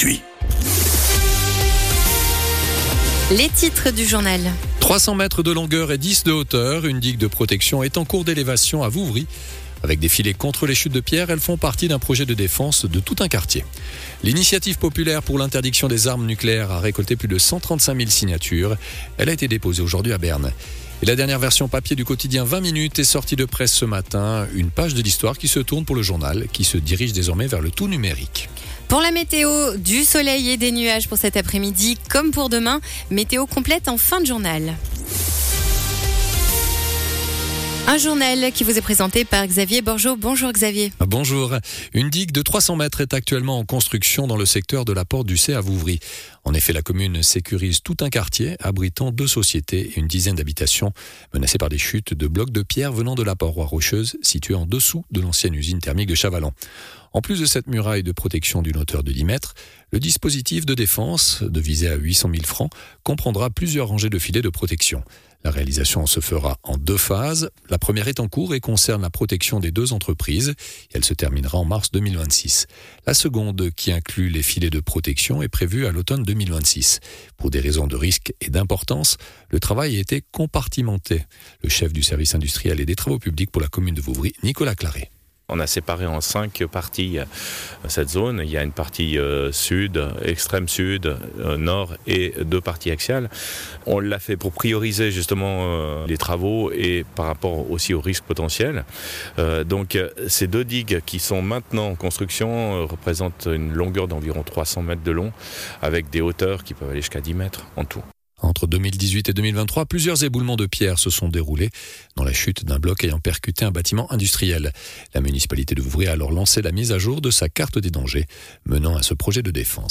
Le journal de midi du 23.12.2025